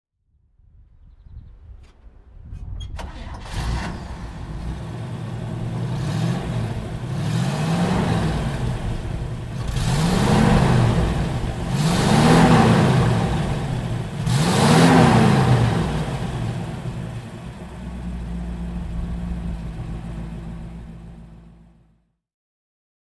Chevrolet Nomad (1960) - Starten und Leerlauf